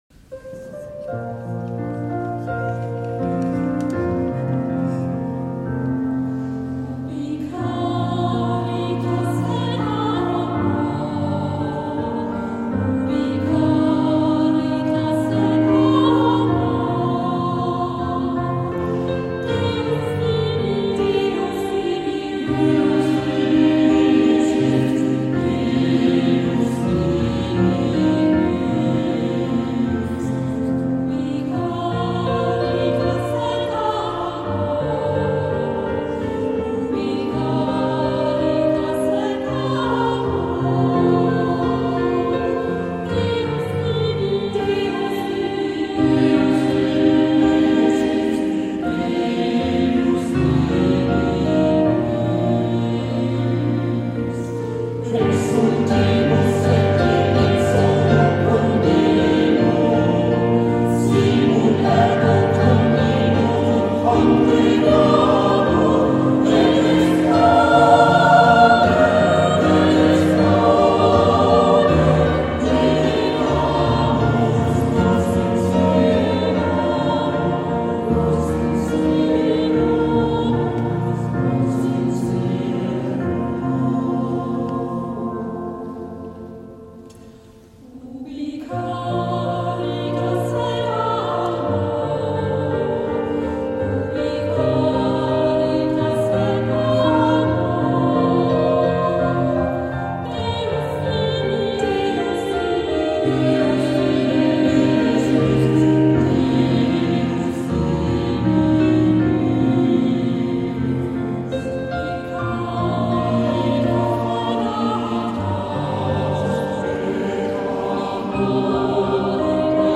Hören Sie sich einige Stücke an, gesungen von der Kantorei und dem JuLifa-Chor (Junge Lieder für alle) der Pfarrei Herz Jesu oder gespielt an der Schuke-Orgel der Stadtpfarrkirche!
Der JuLifa-Chor mit Audrey Snyders „Ubi caritas et amor“: